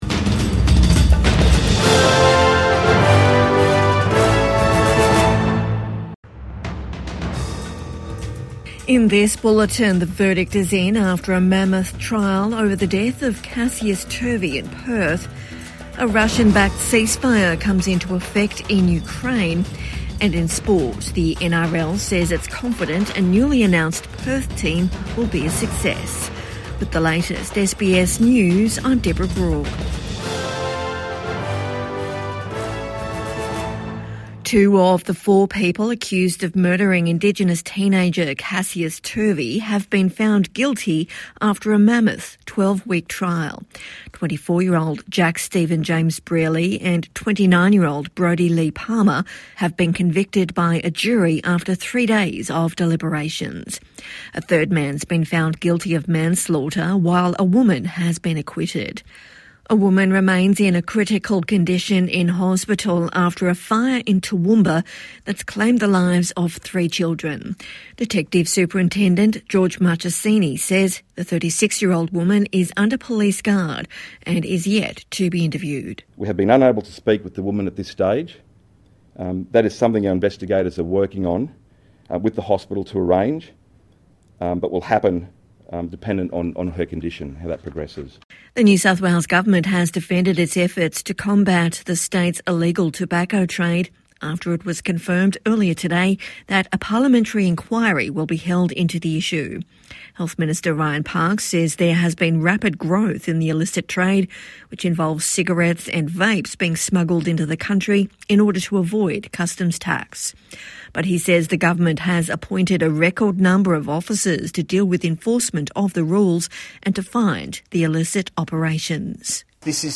Verdict reached in high profile case | Evening News Bulletin 8 May 2025